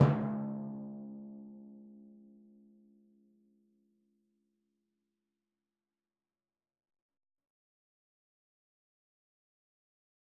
Timpani3_Hit_v4_rr1_Sum.wav